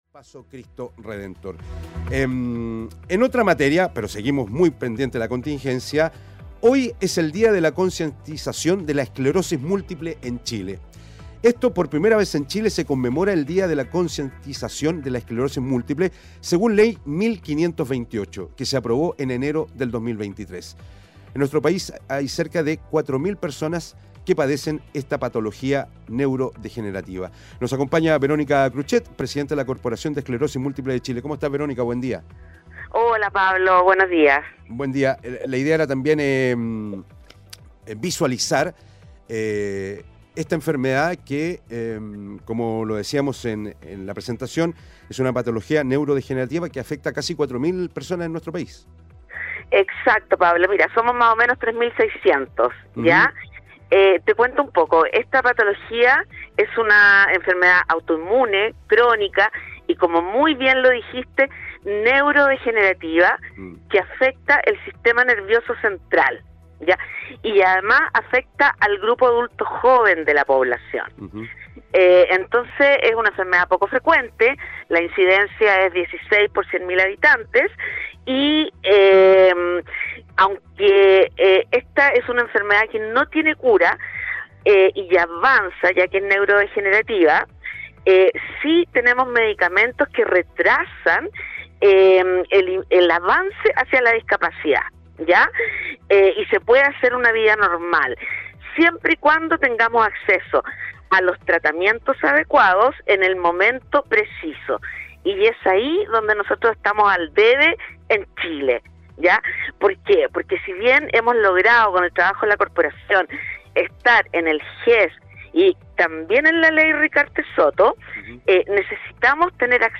entrevista en Expreso Bío Bío